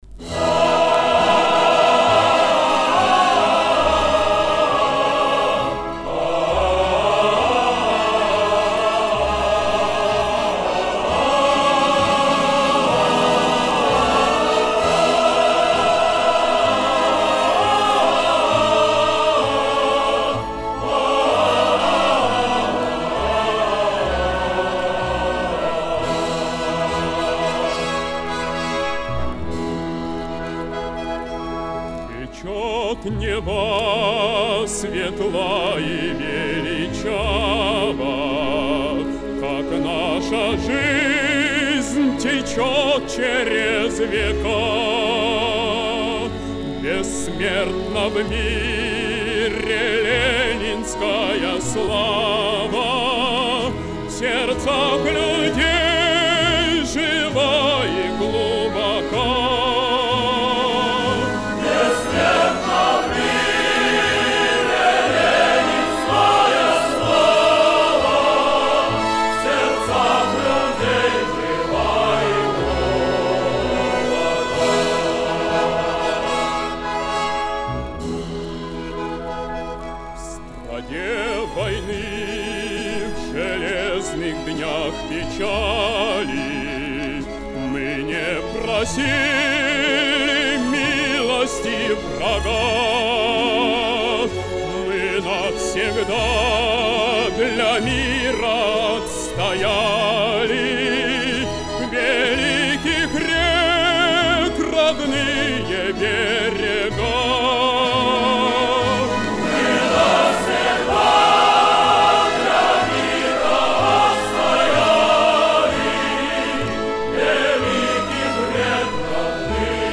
Величественная песня.